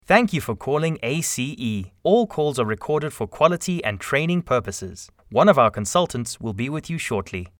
IVR 互动式语音应答